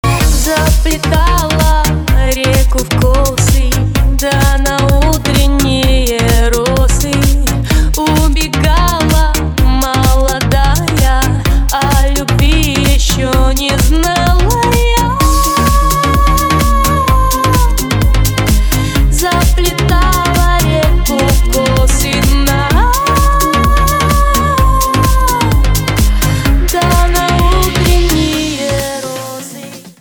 • Качество: 320, Stereo
веселые